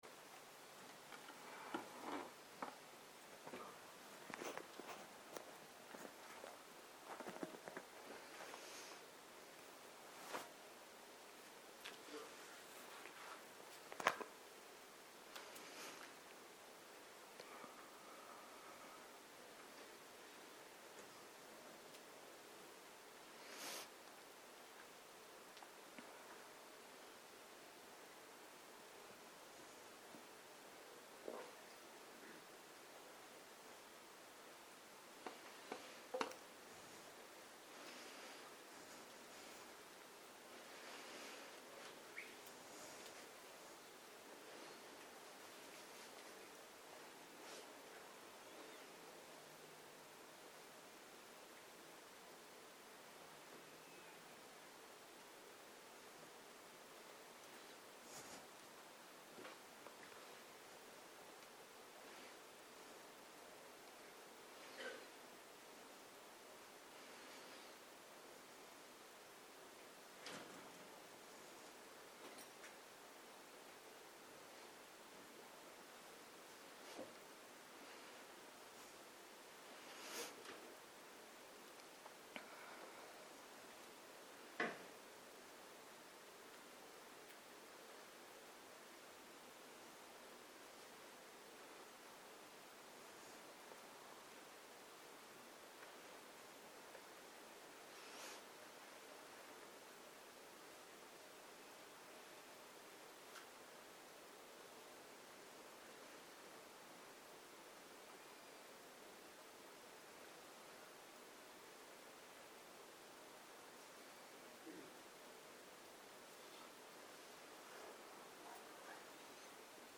מדיטציה מונחית
Dharma type: Guided meditation שפת ההקלטה